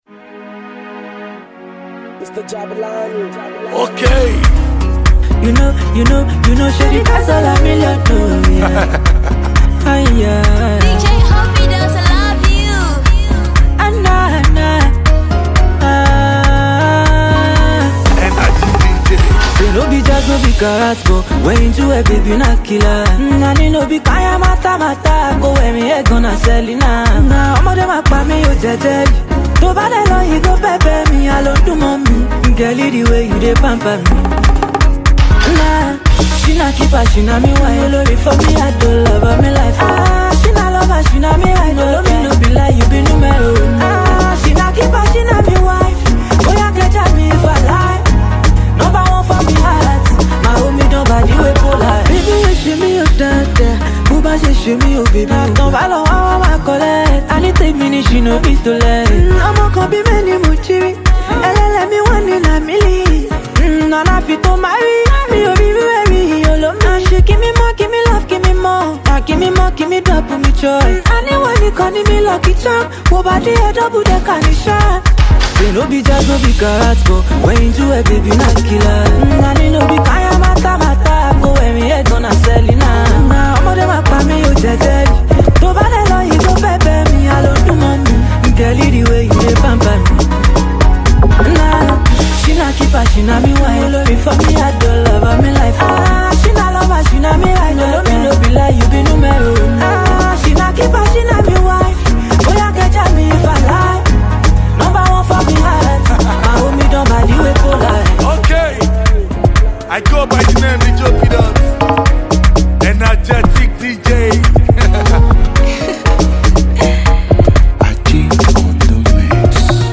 • Genre: Afrobeats